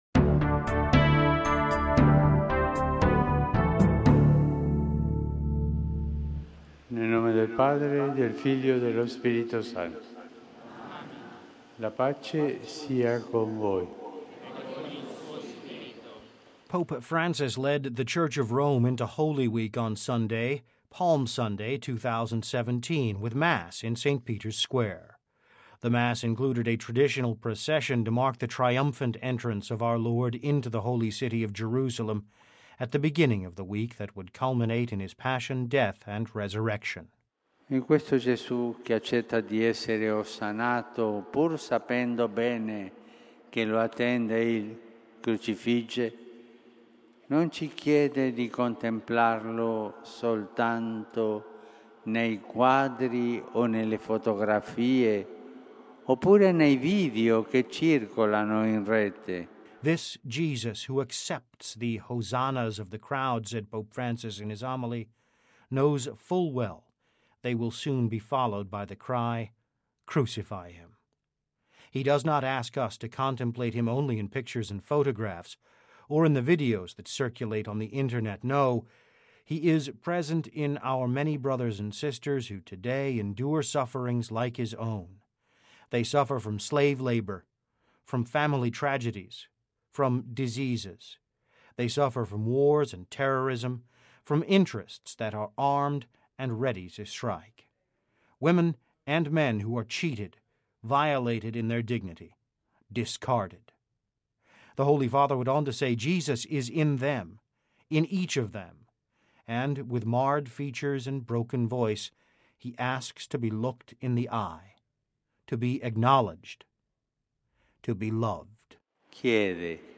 (Vatican Radio) Pope Francis led the Church of Rome into Holy Week on Sunday – Palm Sunday, 2017 – with Mass in St. Peter’s Square.